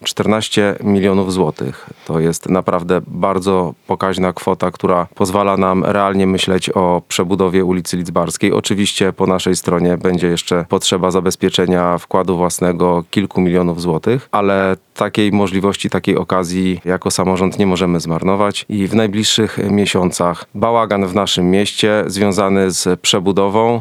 Lidzbarska to jedna z najważniejszych ulic w mieście – mówi burmistrz Żuromina Michał Bodenszac.
Do projektu będzie się musiała dołożyć gmina – informuje burmistrz.